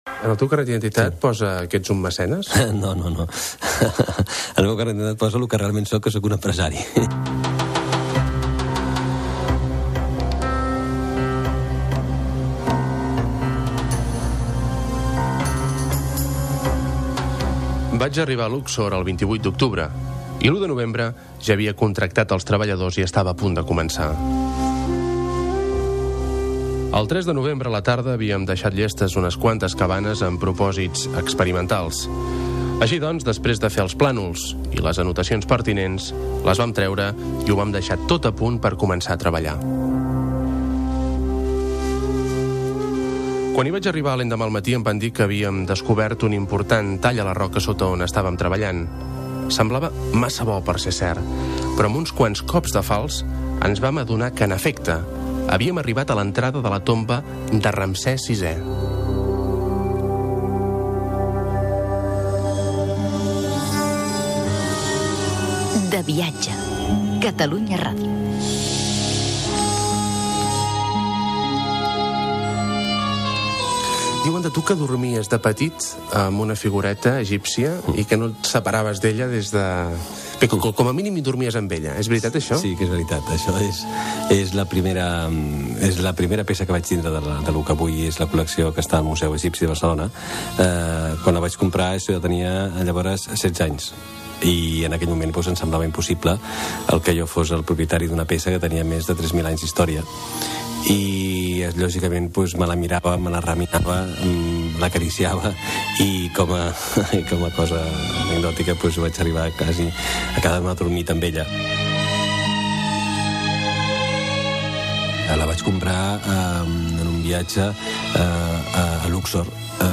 Pregunta a l'invitat. narració sobre la tomba de Ramsès VI a Luxor
Divulgació